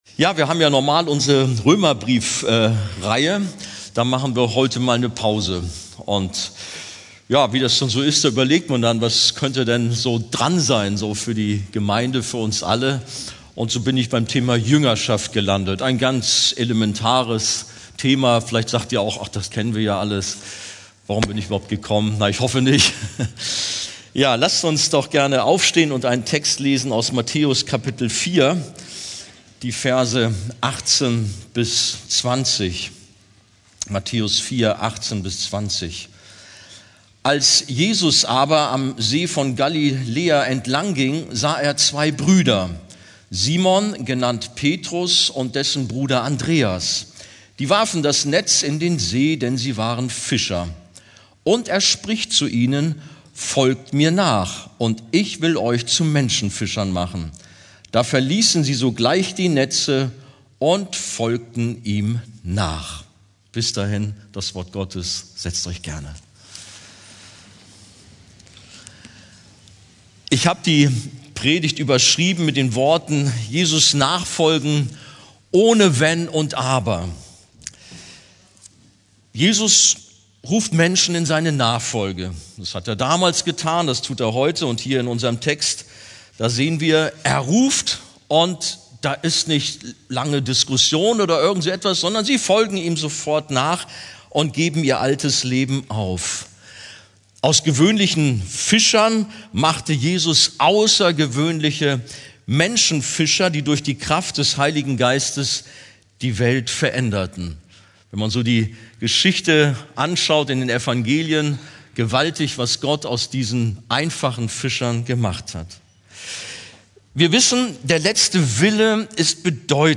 Predigttext: Matthäus 4,18-20